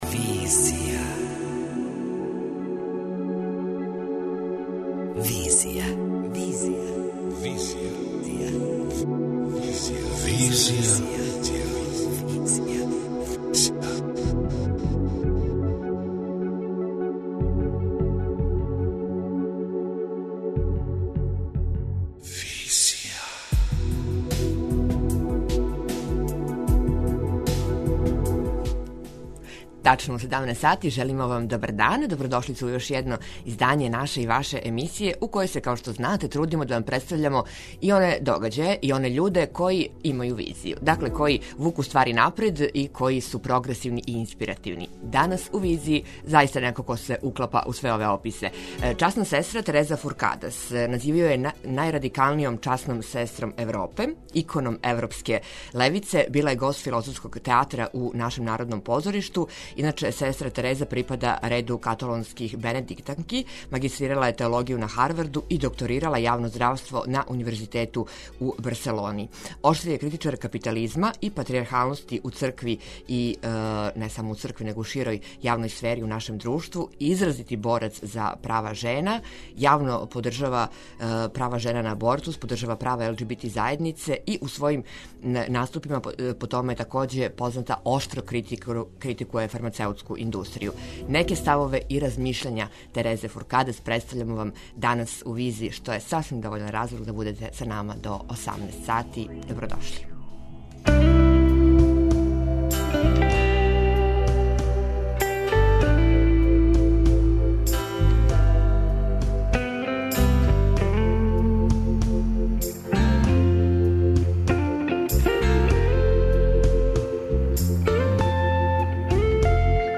Интервју: часна сестра Тереза Фуркадас